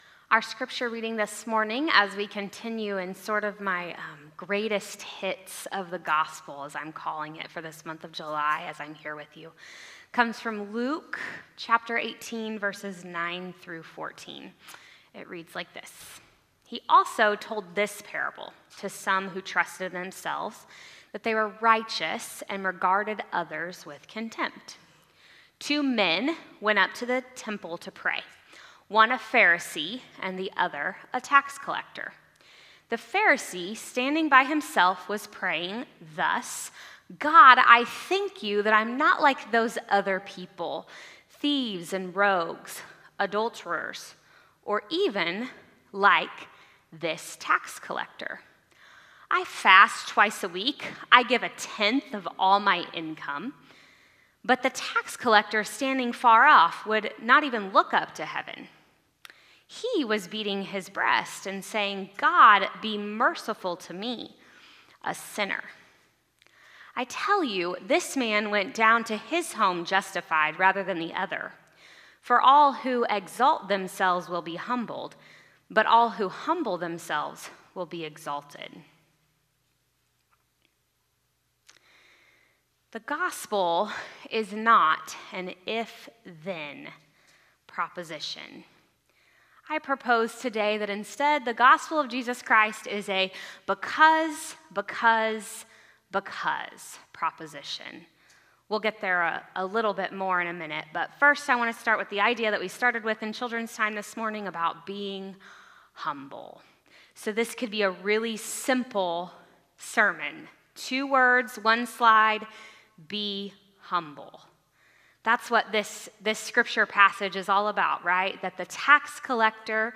Sermons | Harrisonville United Methodist Church